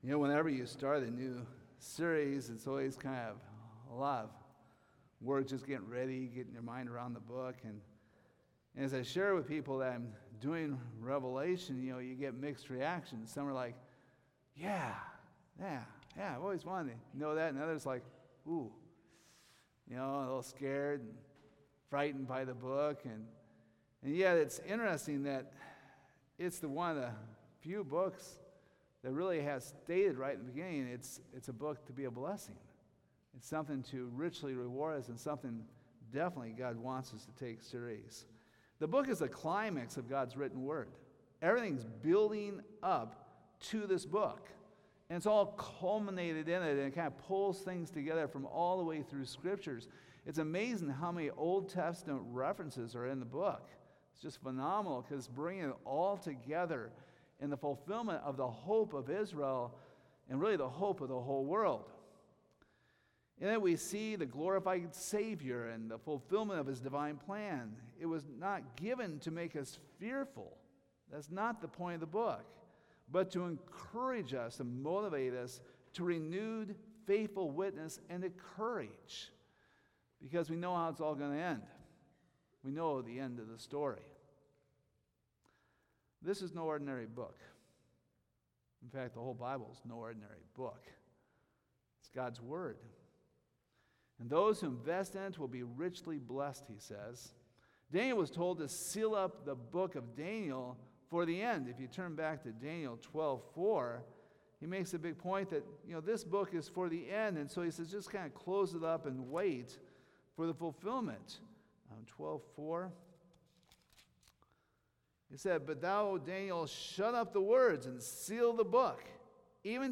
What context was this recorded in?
Revelation 1:1-3 Service Type: Sunday Morning There are a lot of mixed emotions to this last book of the Bible.